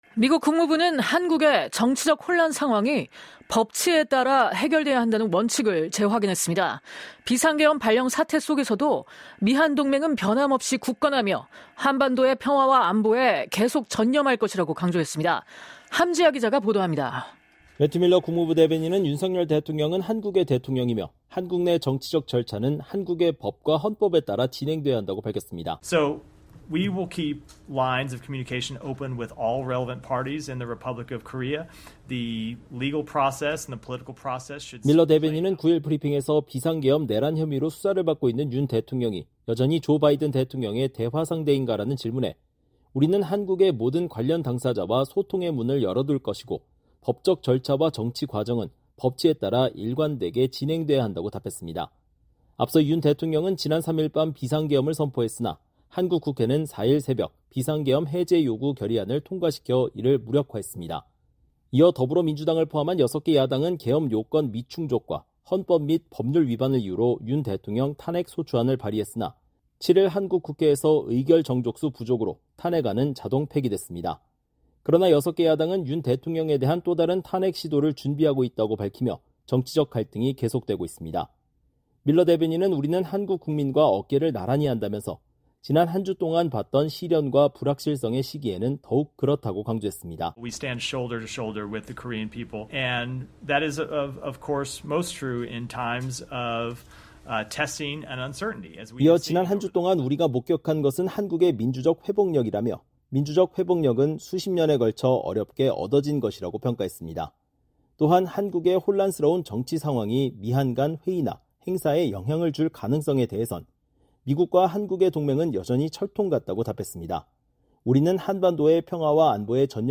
매튜 밀러 국무부 대변인이 9일 브리핑을 하고 있다.